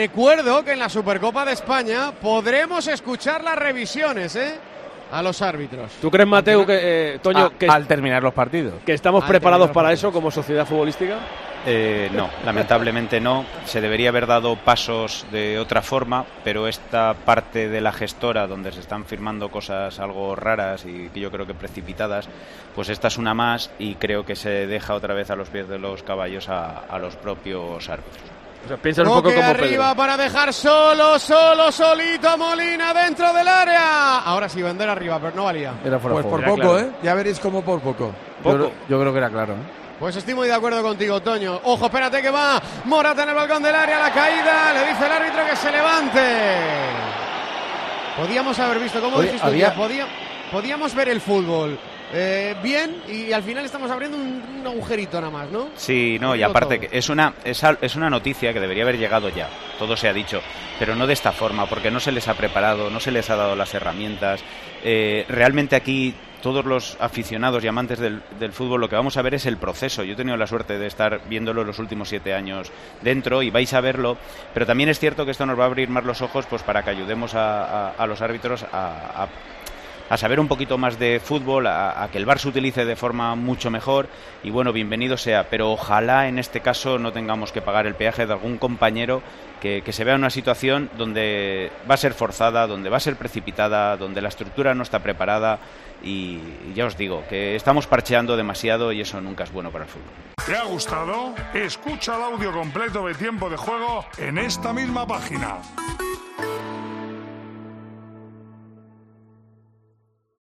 Este sábado, el excolegiado internacional Antonio Mateu Lahoz estuvo comentando las decisiones arbitrales en Tiempo de Juego durante el encuentro entre el Atlético de Madrid y el Sevilla y se mostró algo contrariado por esta noticia y en el audio que aparece a continuación podrás escuchar íntegro su argumento.
El comentario de Mateu Lahoz a raíz de este acuerdo se pudo escuchar durante la retransmisión del Atlético de Madrid-Sevilla que se disputó el sábado 23 de diciembre en el Cívitas Metropolitano y que sirivió para cerrar el año.